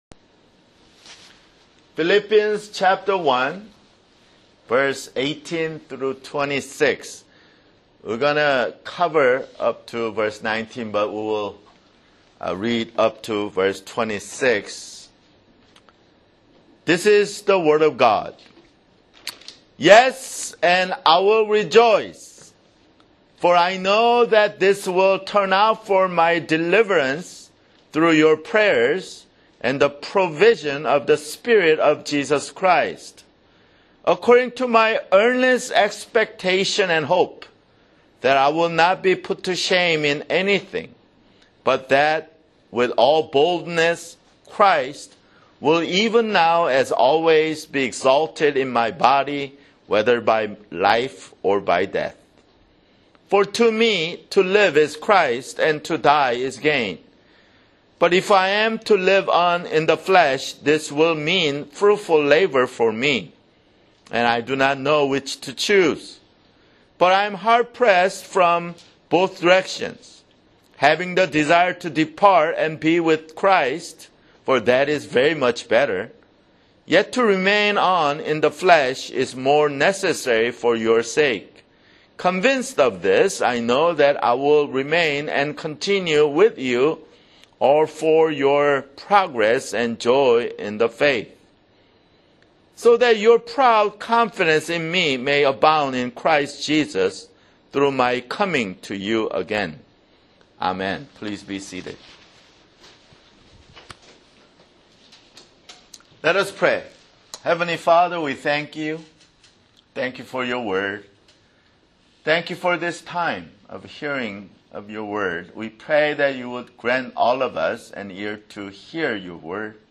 [Sermon] Philippians (15)